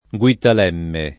vai all'elenco alfabetico delle voci ingrandisci il carattere 100% rimpicciolisci il carattere stampa invia tramite posta elettronica codividi su Facebook Guittalemme [ gU ittal $ mme ] top. — immaginaria città dei guitti